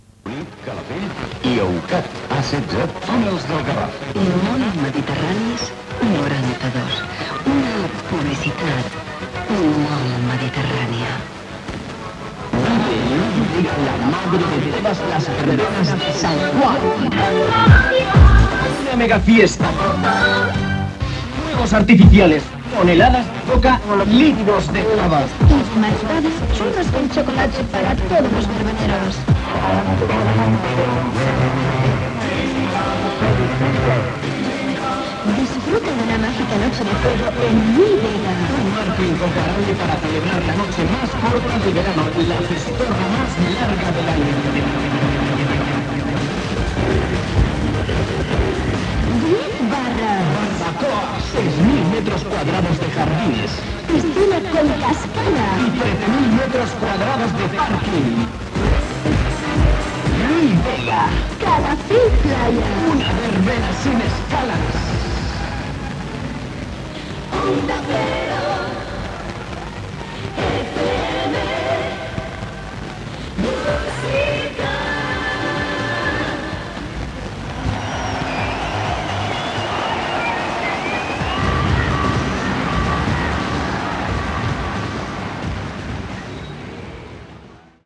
7f50f6ec2466f46b1a78b5373fff5917bda613ae.mp3 Títol Onda Cero Música Emissora Onda Cero Vilanova Cadena Onda Cero Radio Titularitat Privada estatal Descripció Publicitat, indicatiu i tema musical.